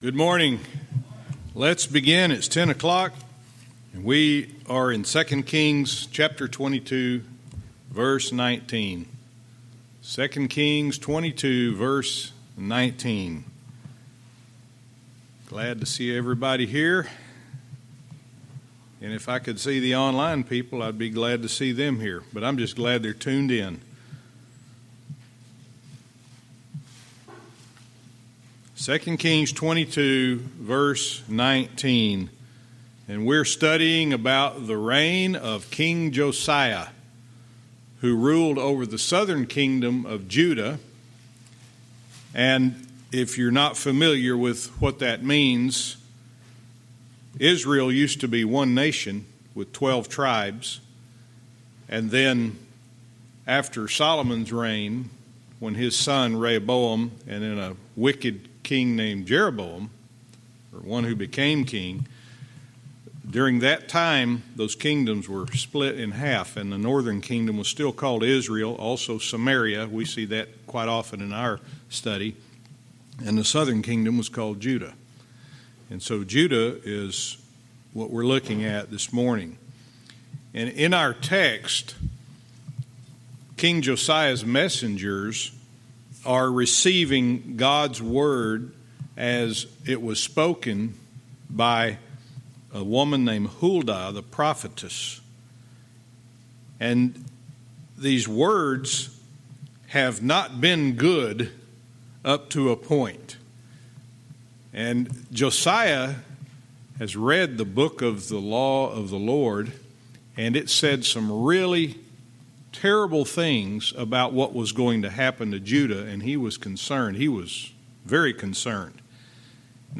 Verse by verse teaching - 2 Kings 22:19-20